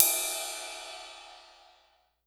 Closed Hats
hat_14.wav